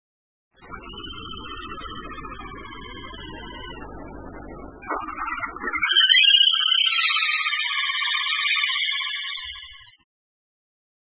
2124c「鳥の鳴声」
〔カイツブリ〕キリキリキリキリ／淡水湖沼に棲み巣周辺の縄張りで鳴く，普通・留鳥
kaituburi.mp3